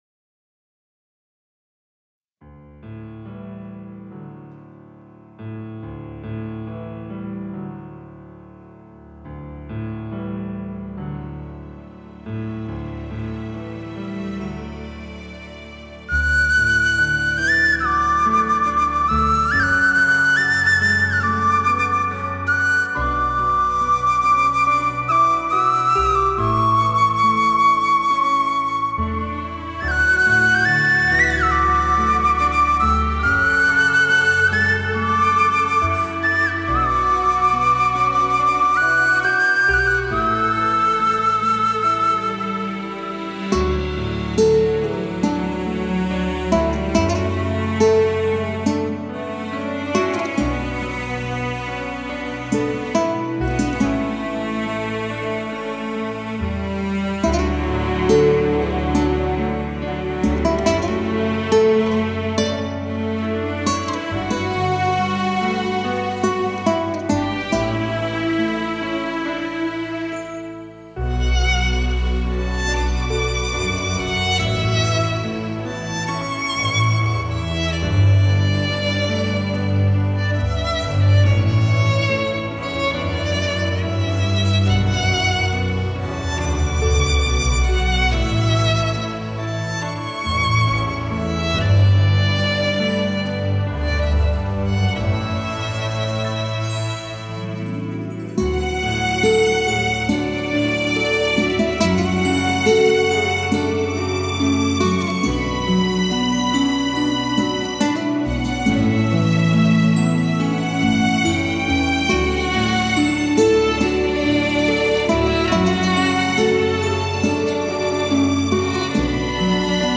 专辑语言：轻音乐